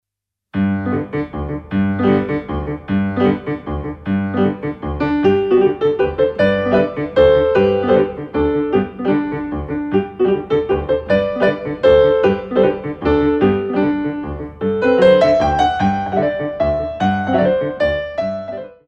3/8 - 8x8